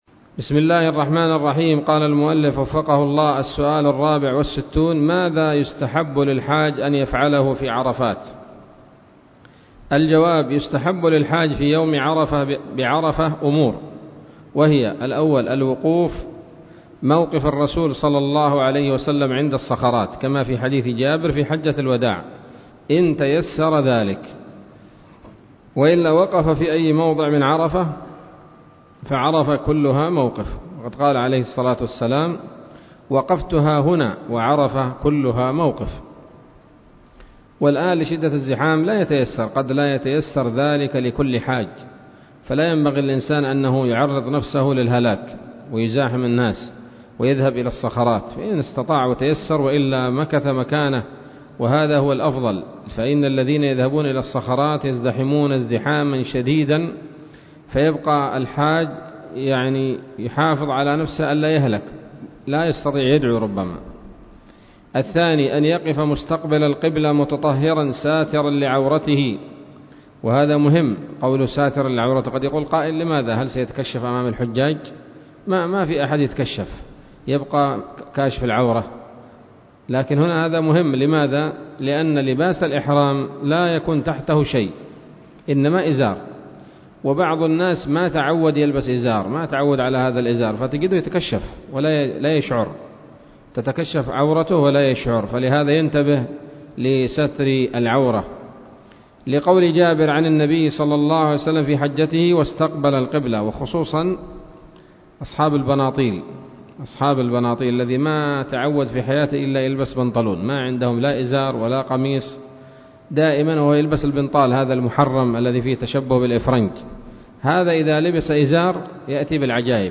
الدرس الثلاثون من شرح القول الأنيق في حج بيت الله العتيق